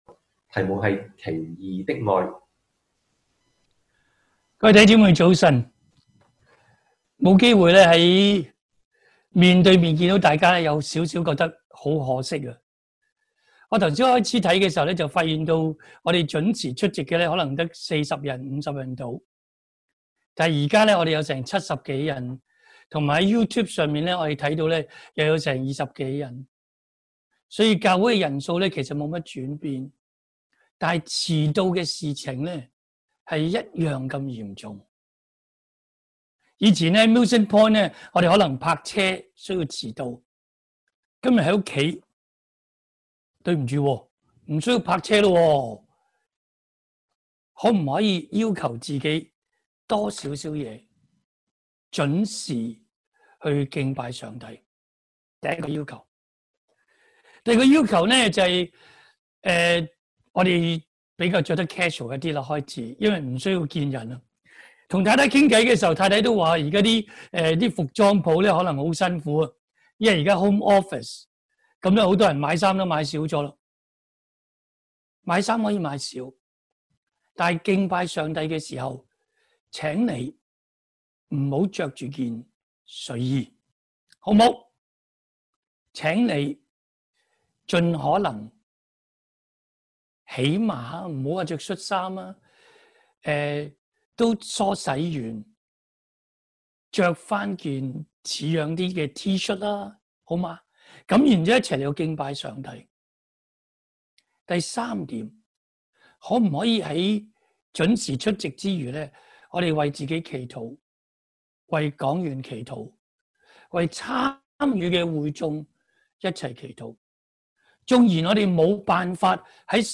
Strange” love! 05/07/2020 Bible Text: 何西阿書1:1-2:1 (Hosea) | Preacher